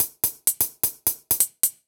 Index of /musicradar/ultimate-hihat-samples/128bpm
UHH_ElectroHatD_128-04.wav